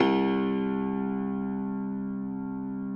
53r-pno02-C0.aif